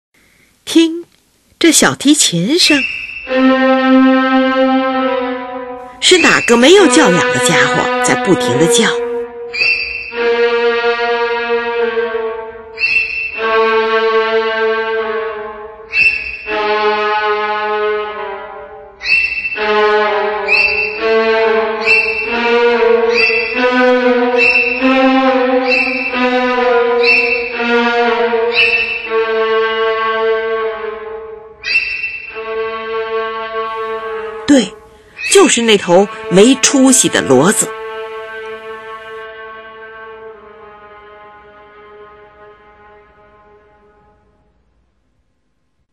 听，这小提琴声，是哪个没教养的家伙在不停地叫？
这是一首用小提琴模仿骡子叫声的戏谑性的小曲子。